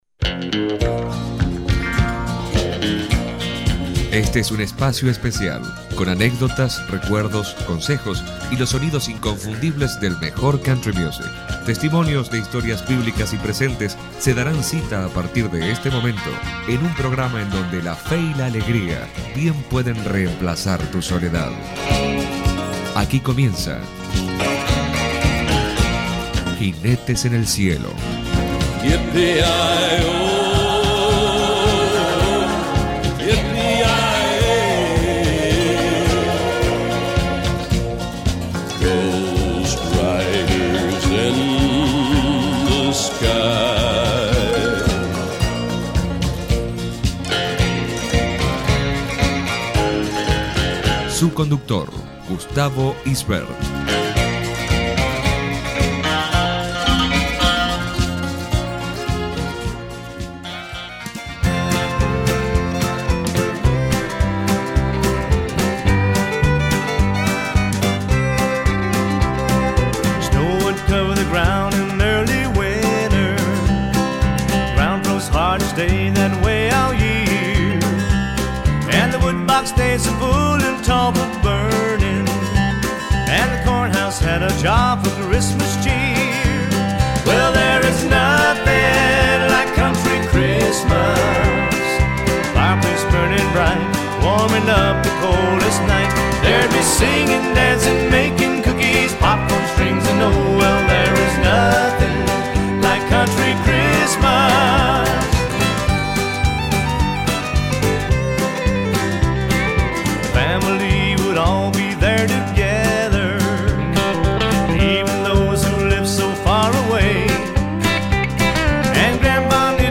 Navidad es Jesús .- ¡Un programa de radio navideño!